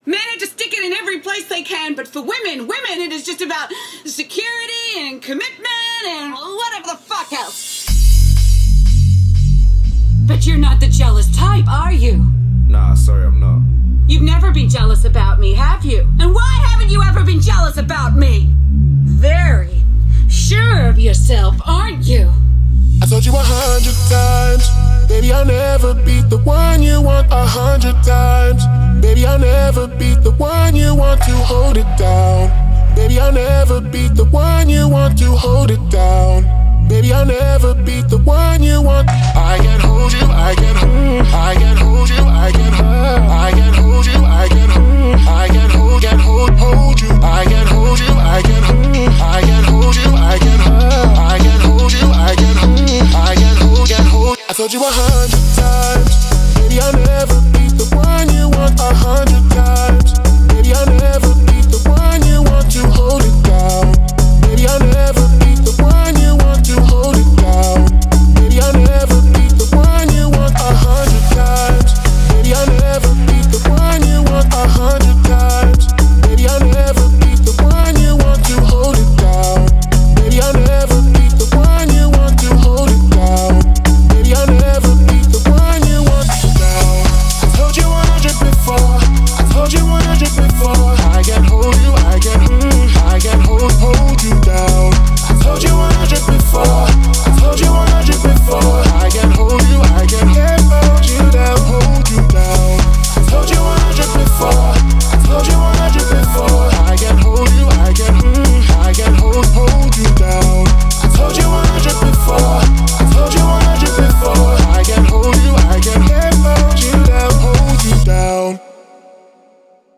BPM122
MP3 QualityMusic Cut